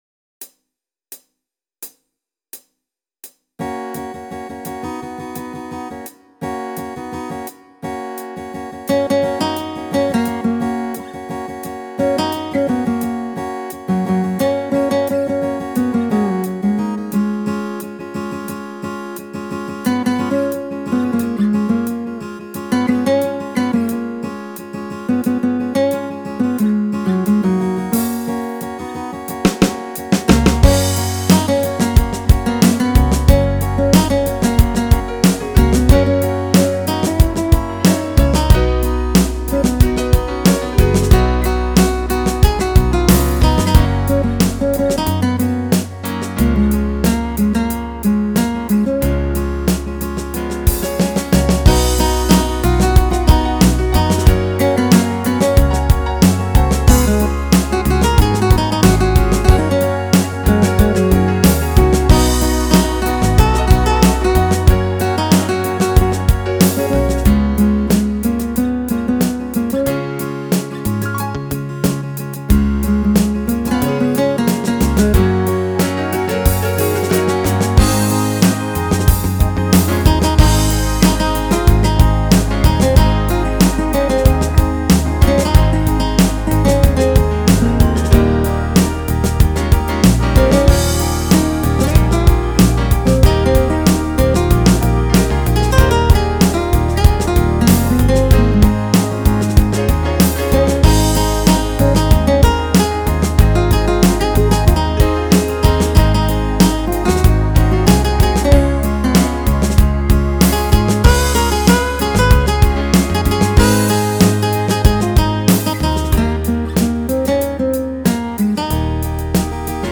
Especially the keyboards – who knew?
And the bass player wasn’t any slouch either.